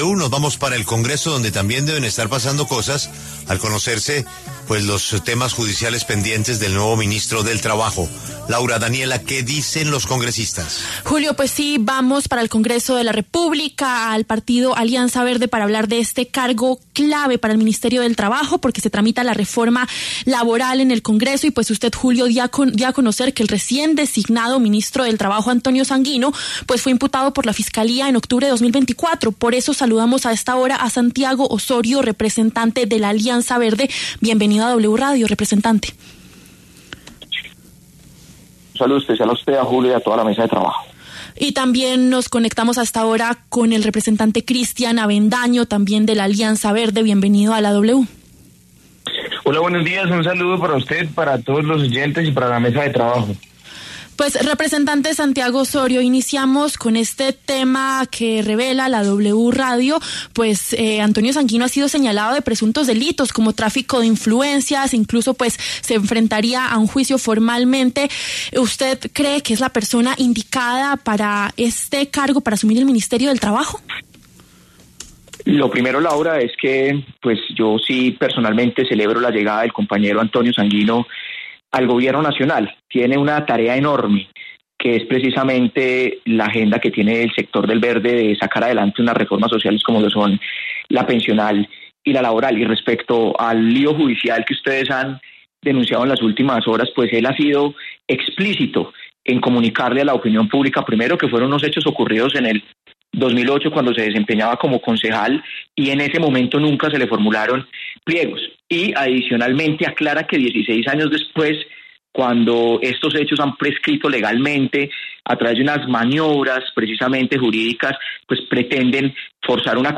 Los representantes de la Alianza Verde, Santiago Osorio y Cristian Avendaño, debatieron en La W.